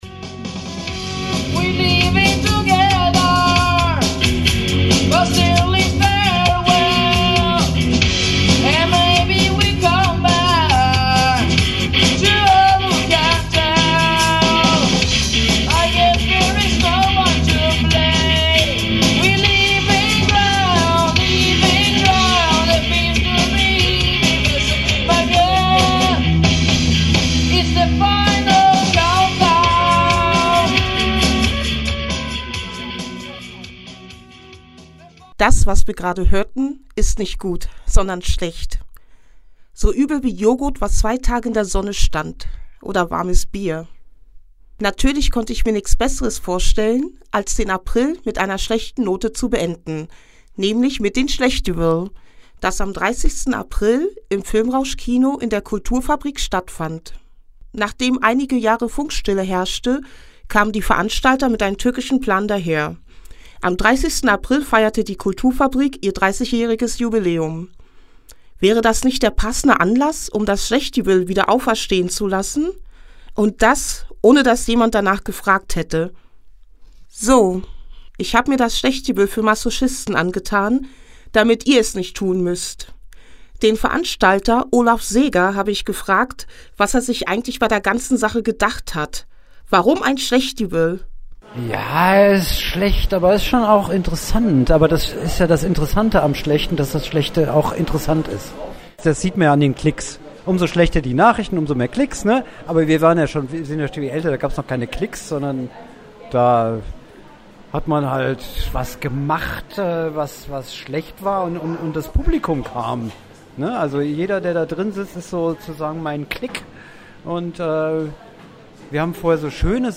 Am 30. April feierte die Kulturfabrik ihr 30-jähriges Jubiläum, und zu diesem Anlass fand das Schlechtival nach jahrelanger Pause wieder statt.
Die Vorlesung von einem Beipackzettel für ein Magen Darm Medikament kam auch auf die Bühne.